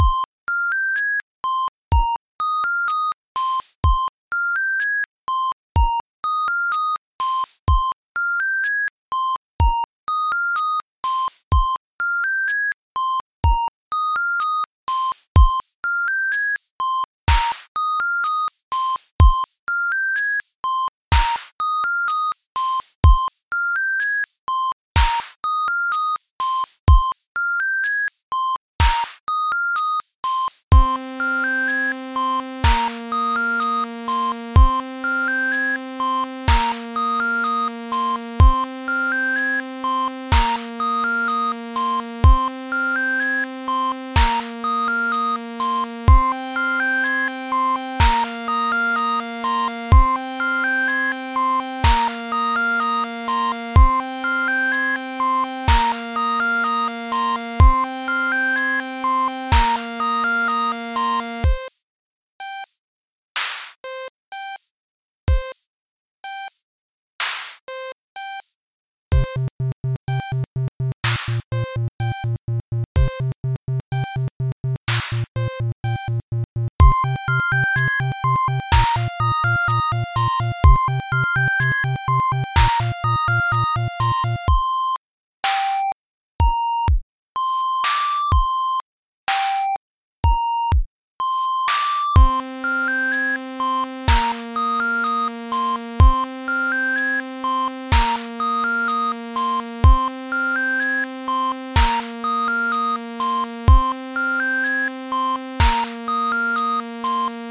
Backing Track
FV_hh_Beat2_p2.mp3